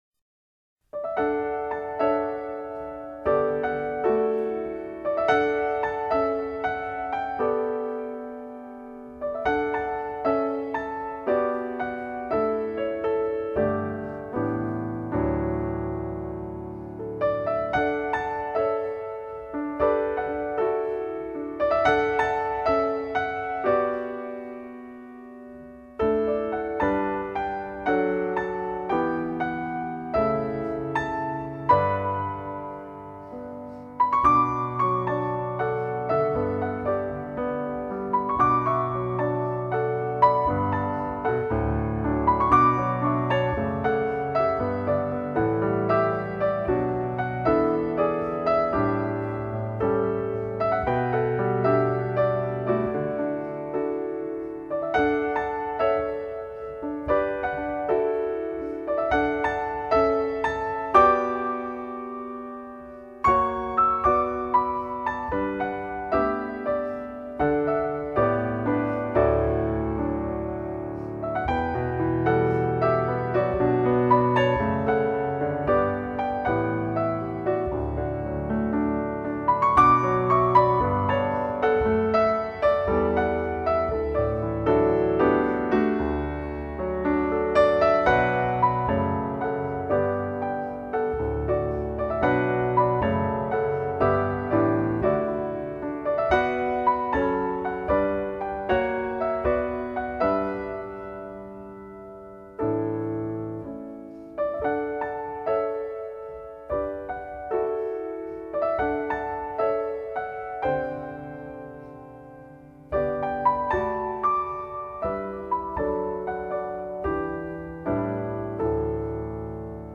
类　别： NewAge
清澈而没有过多的粘稠，温柔却又溪水长流。
更加入柔情的小提琴和单簧管伴奏，听起来非常温暖和舒适。
在清幽的音乐氛围里，清澈的钢琴音色，恰如其分地妆点出绮丽的光影，
融合大提琴与小提琴婉约动人的低诉，形成比例完美的弦乐合奏，